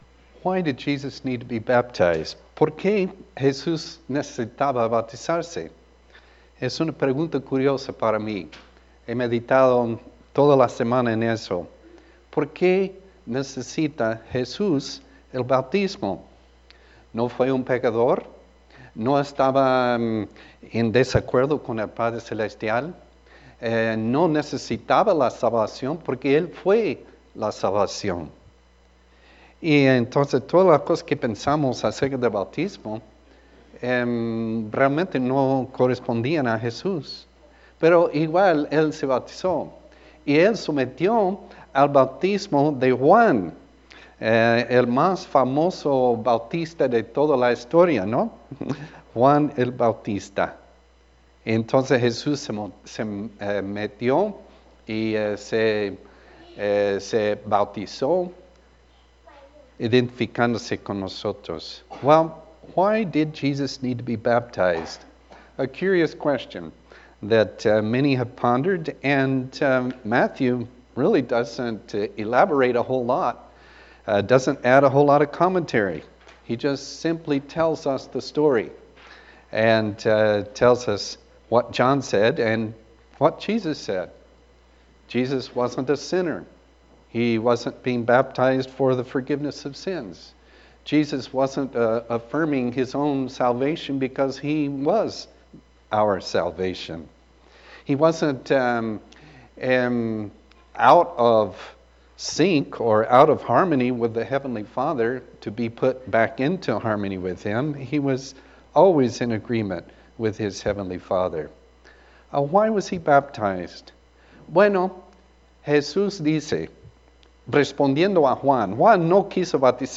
Bilingual Sermons